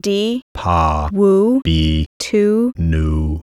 Both grammars involved sequences of syllables spoken by a female and male speaker - A syllables were spoken by the female and were ba, di, yo, tu, la, mi, no, or wu; B syllables were spoken by the male and were pa, li, mo, nu, ka, bi, do, or gu.
di-pa-wu-bi-tu-nu.wav